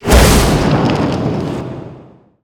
batrider_flamebreak_cast.wav